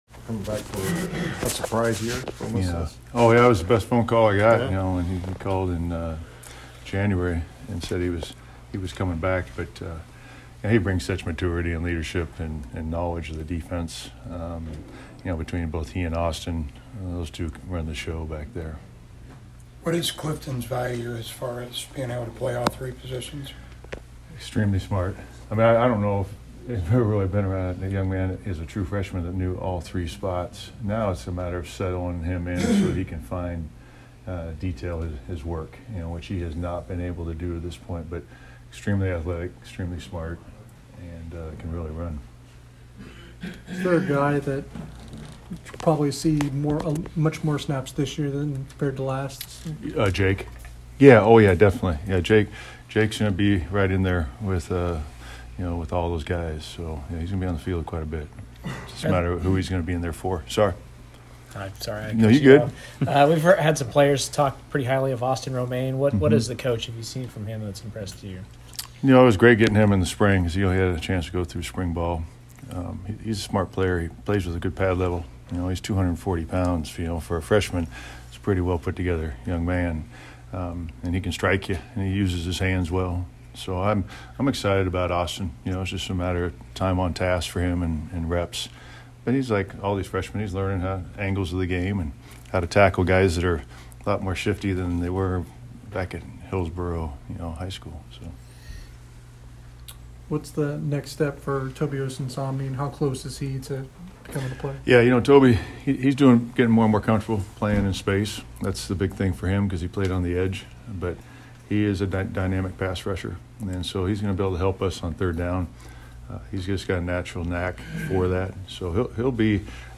K-State Holds Second Preseason Press Conference